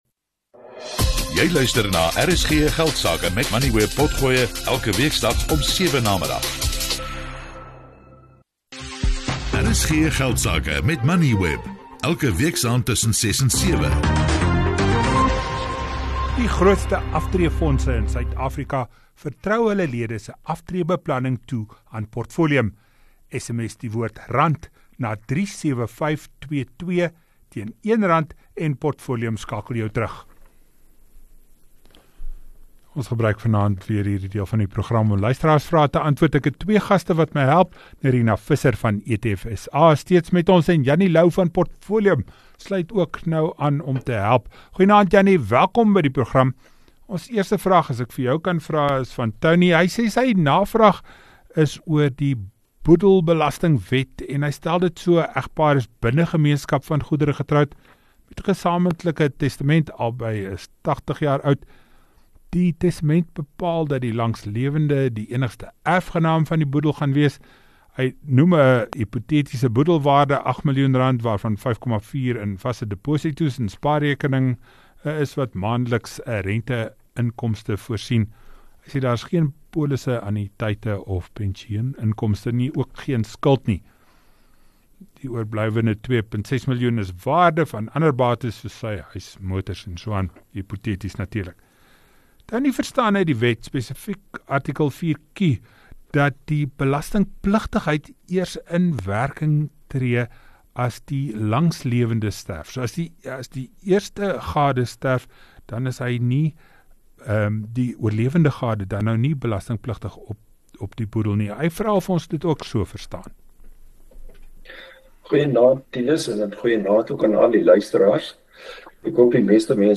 ไม่ต้องลงชื่อเข้าใช้หรือติดตั้ง Kenners antwoord luisteraarsvrae. Spaar so vir ‘n mediese noodgeval.
Dit fokus op belangrike sakenuus, menings en beleggingsinsigte. RSG Geldsake word elke weeksdag tussen 18:10 en 19:00 op RSG (101 – 104 FM) uitgesaai.